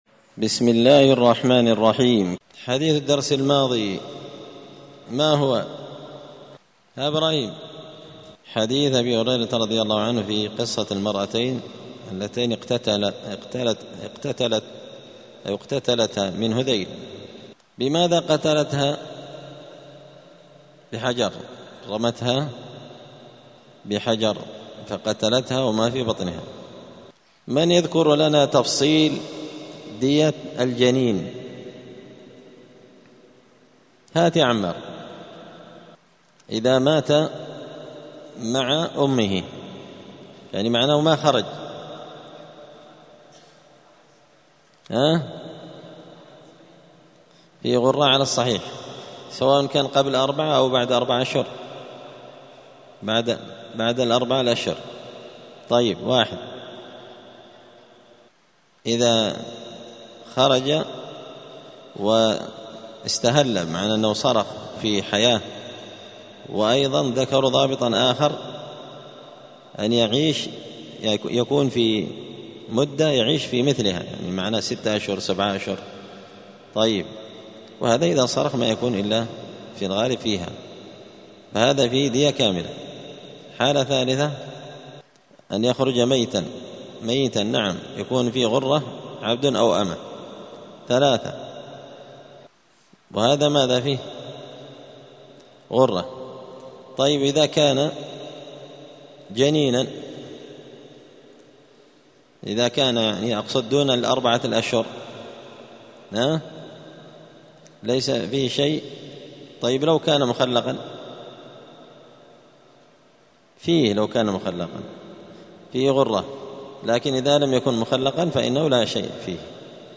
*الدرس الحادي عشر (11) {تابع لباب الجنين إذا مات بسبب الجناية}*